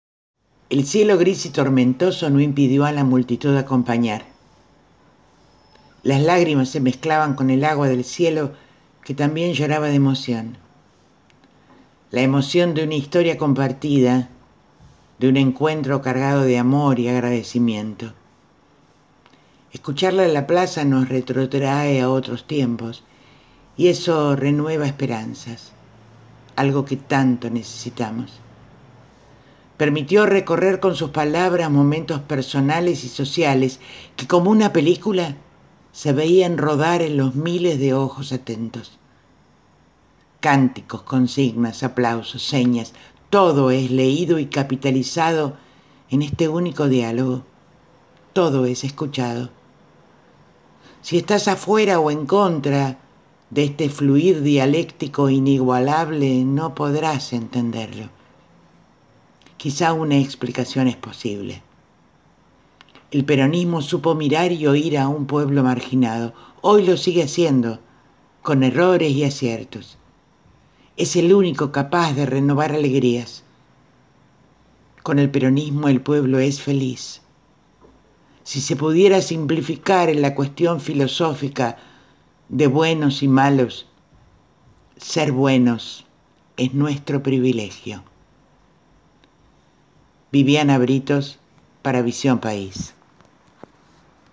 EL 25 EN LA PLAZA
El cielo gris y tormentoso no impidió a la multitud acompañar.
Cánticos, consignas, aplausos, señas, todo es leído y capitalizado en este único diálogo.